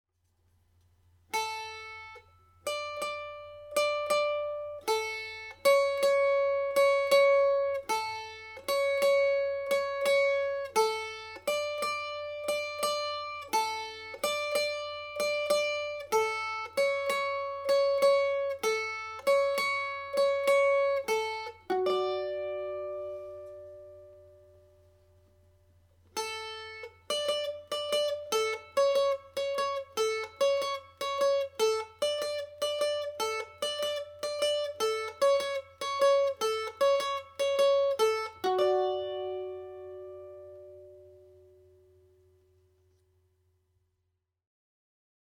Polka (D Major)
Intro Riff